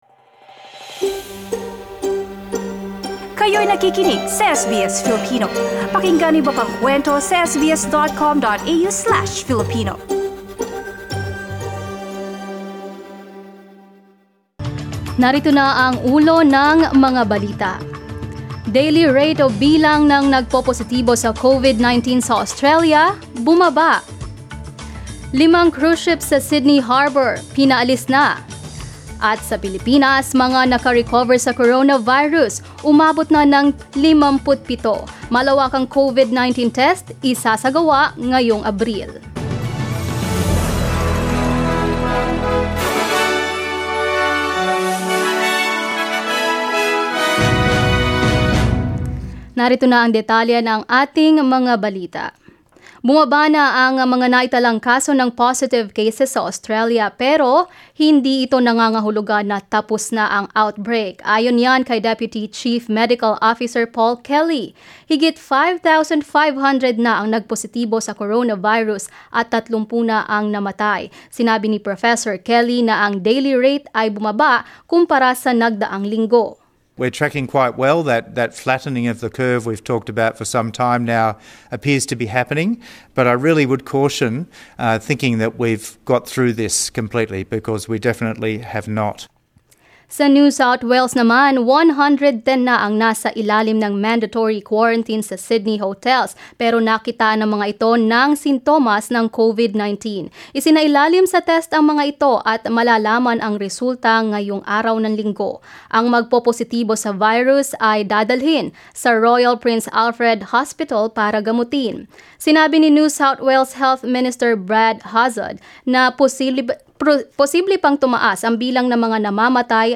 Mga balita ngayong Linggo, ika-5 ng Abril